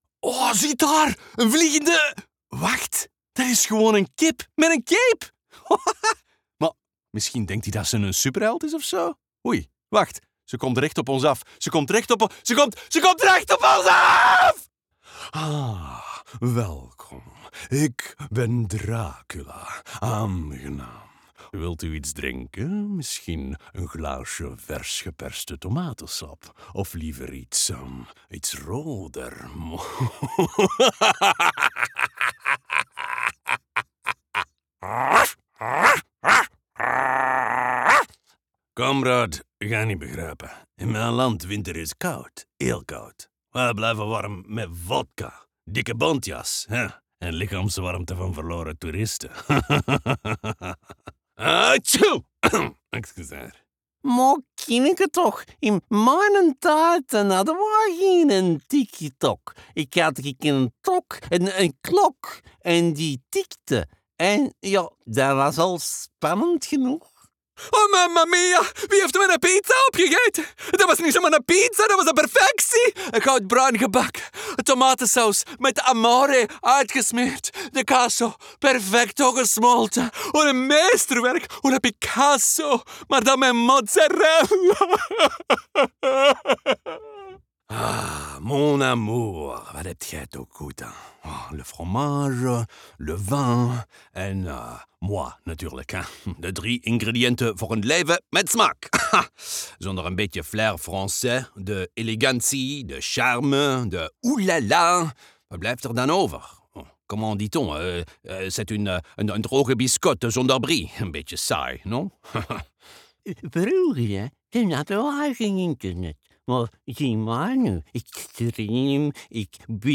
Male
Approachable, Assured, Authoritative, Bright, Character, Confident, Conversational, Cool, Corporate, Deep, Energetic, Engaging, Friendly, Funny, Gravitas, Natural, Posh, Reassuring, Sarcastic, Smooth, Soft, Upbeat, Versatile, Wacky, Warm, Witty
Microphone: Sennheiser MKH416 and Neumann TLM103
Audio equipment: Studiobricks One Custom, UA Apollo Twin